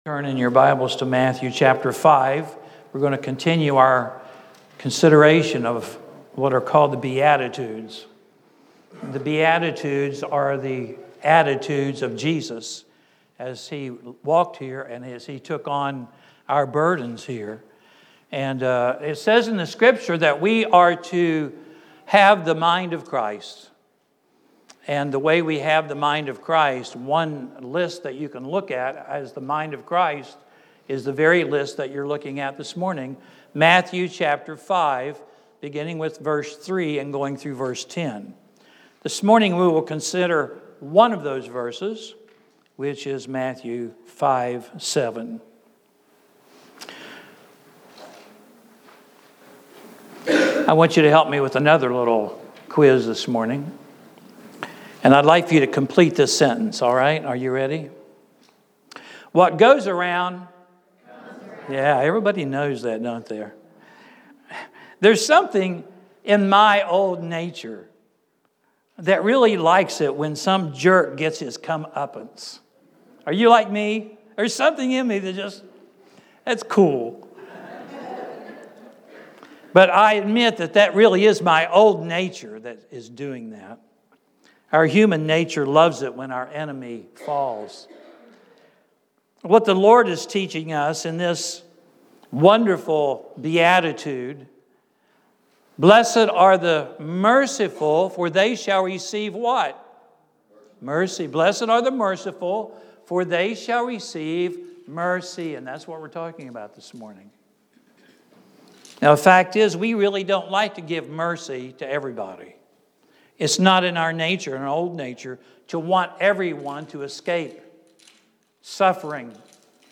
Sermons | Beulah Church